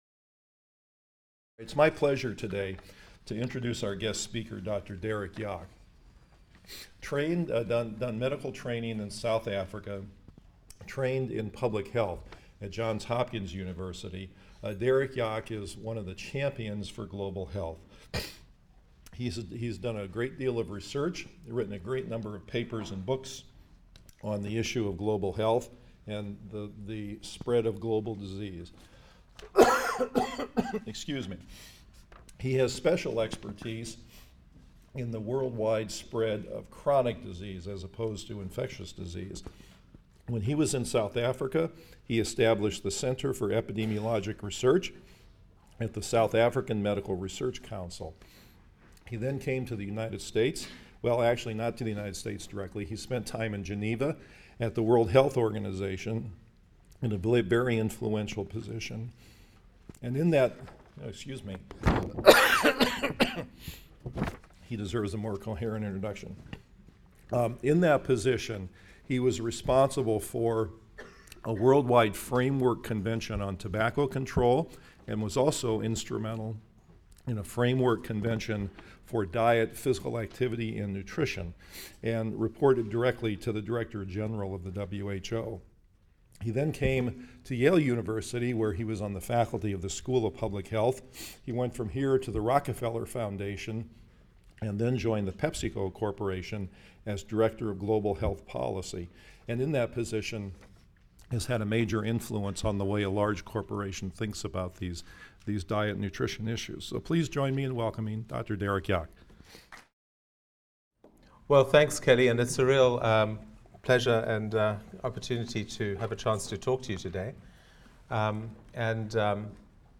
PSYC 123 - Lecture 14 - Perspectives of the Food Industry